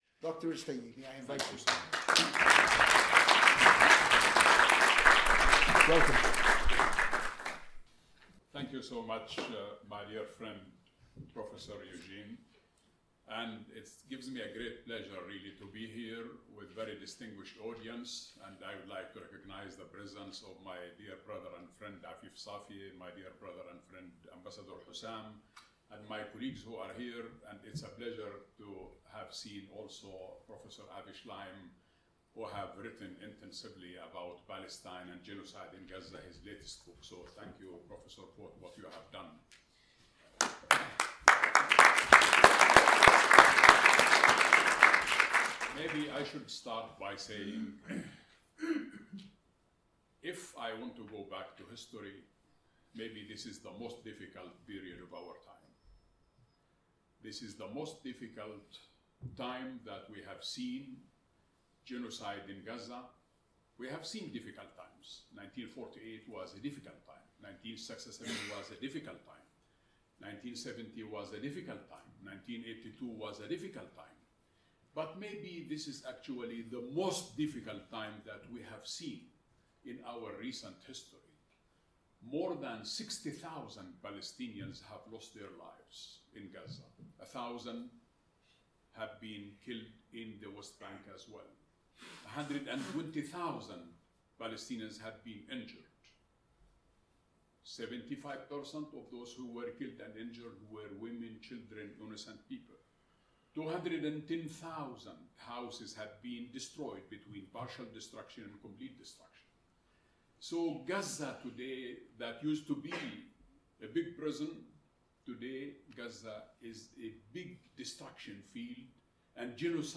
Speech delivered by H.E. Dr Mohammad Shtayyeh, Chairman of the Palestine Research Centre and former Palestinian Prime Minister, at a symposium held at the MEC on Friday 9 May 2025.The symposium was entitled ‘Toward an Inclusive Archaeological and Historical Narrative of Palestine: The Archaeology and History of Palestine’.